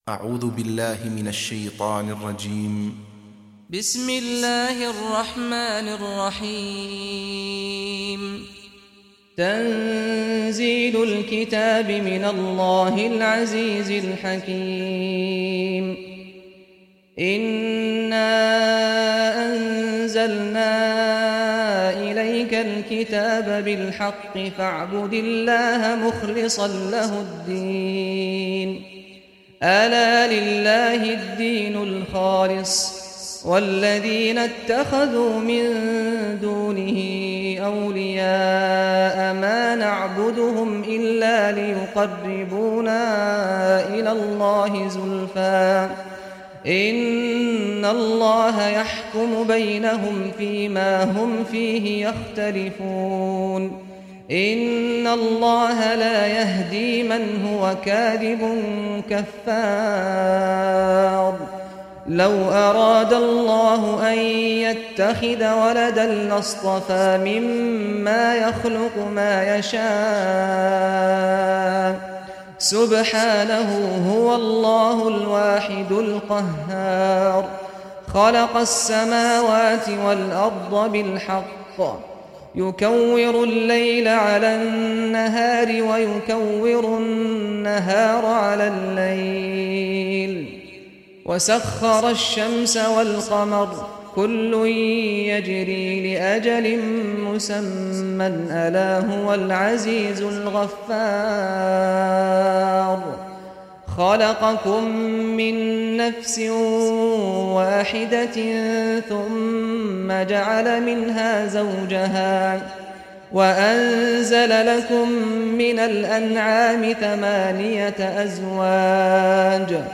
Surah Az-Zumar Recitation by Sheikh Saad al Ghamdi
Surah Az-Zumar, listen or play online mp3 tilawat / recitation in Arabic in the beautiful voice of Sheikh Saad al Ghamdi.